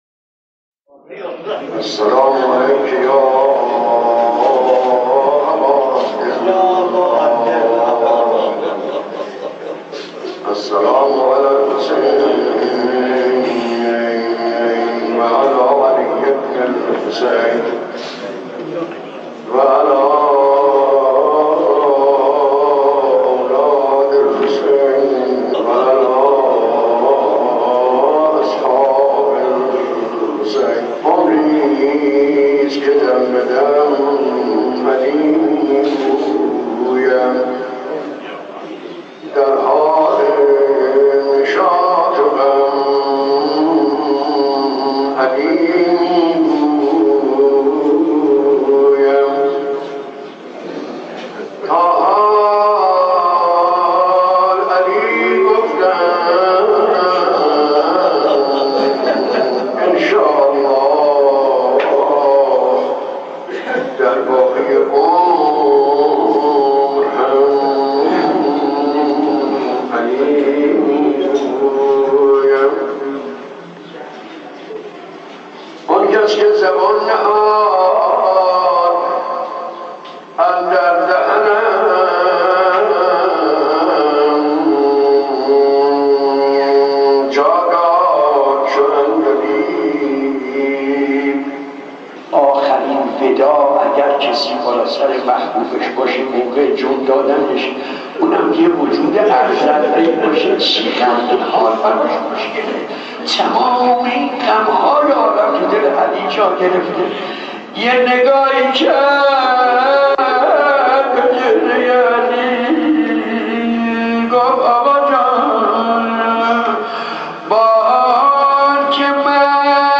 مداحی‌های طهران قدیم
مداحی قدیمی